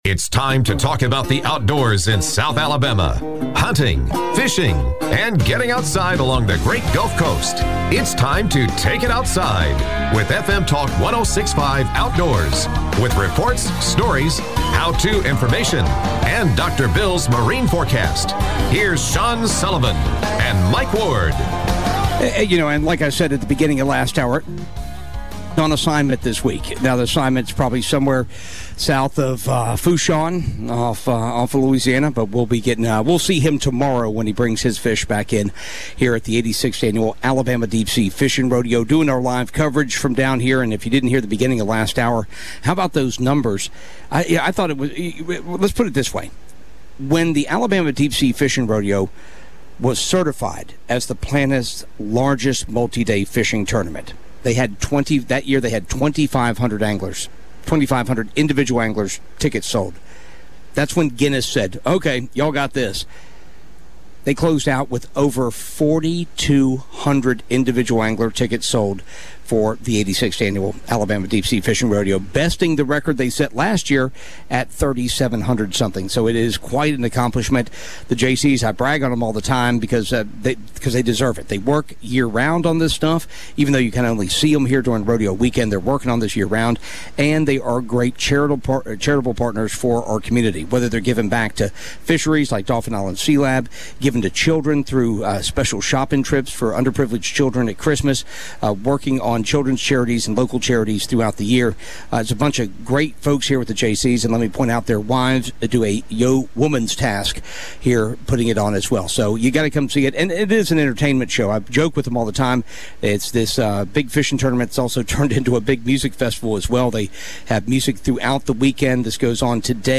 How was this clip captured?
the Outdoors show from the Alabama Deep Sea Fishing Rodeo